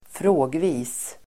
Ladda ner uttalet
Uttal: [²fr'å:gvi:s]